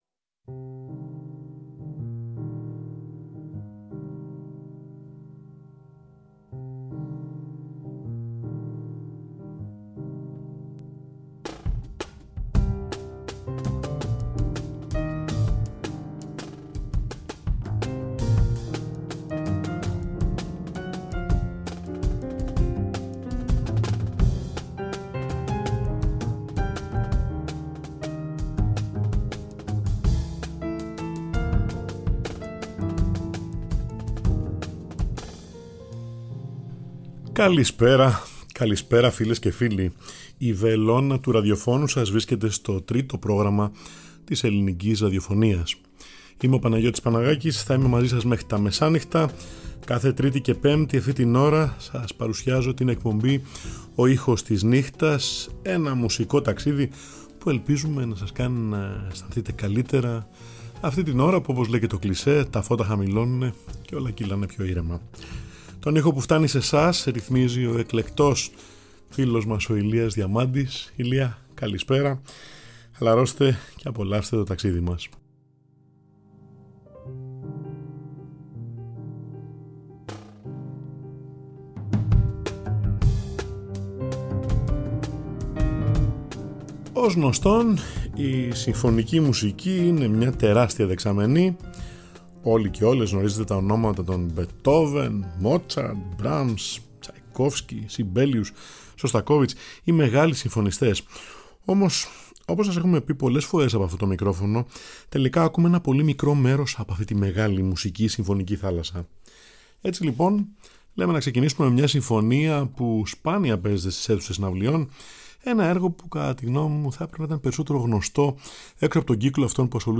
Ο θρυλικός πιανίστας
κινηματογραφική μουσική
ο Εσθονός πιανίστας της jazz